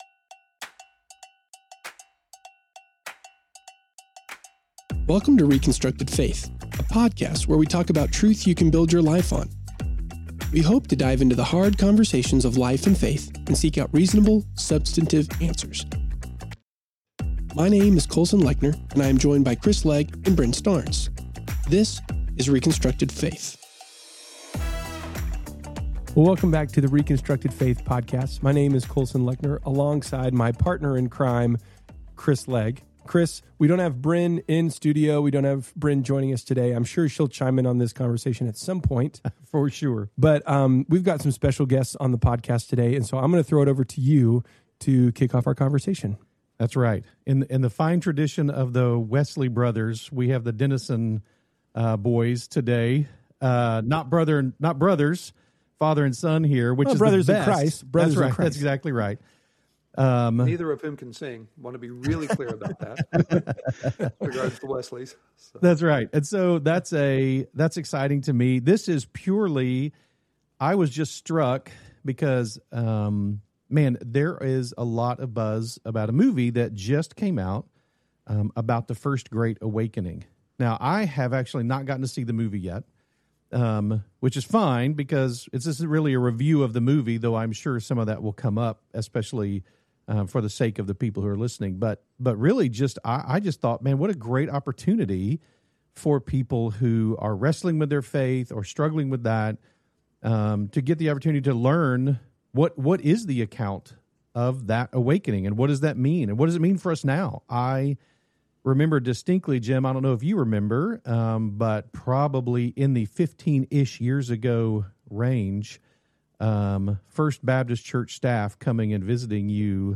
Whether you’re longing for renewal in your own life, your church, or your community, this conversation offers both historical insight and present-day encouragement.